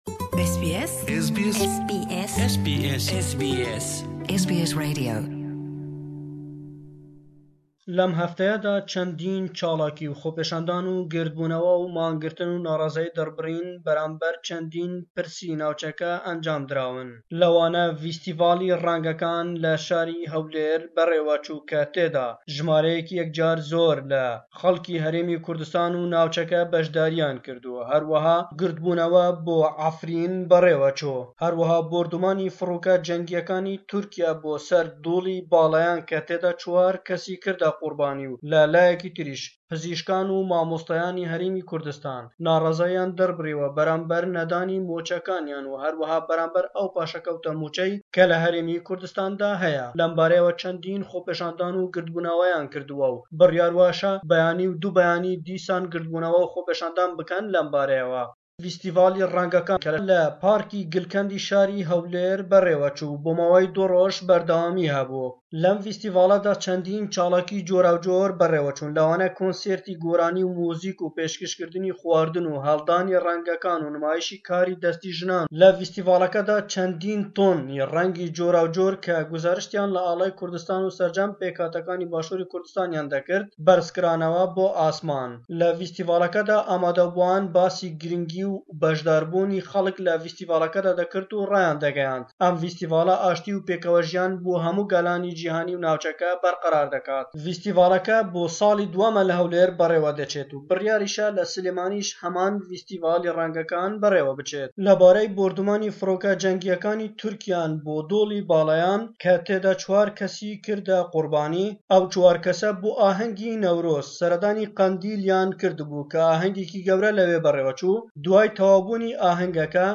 Le em raportey peyamnêrman